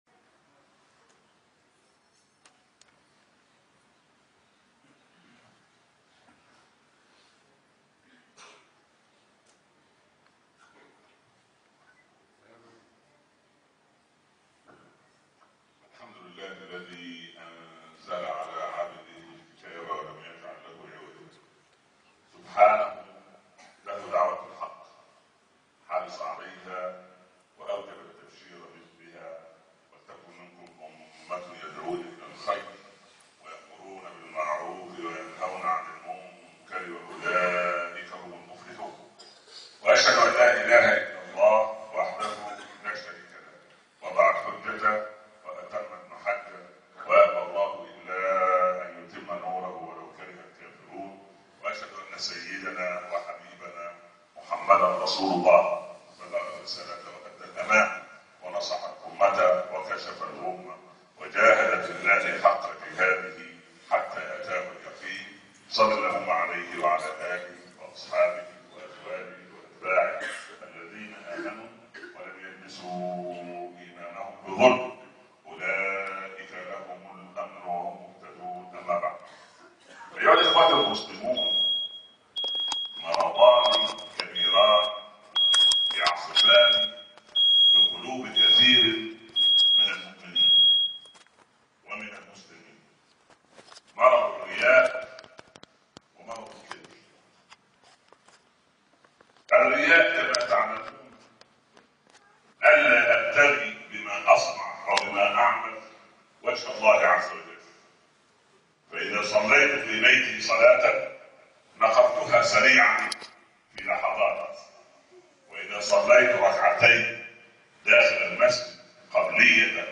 مدينة الرضا (خطبة الجمعة) - الشيخ عمر بن عبدالكافي